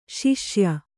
♪ śiṣya